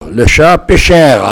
locutions vernaculaires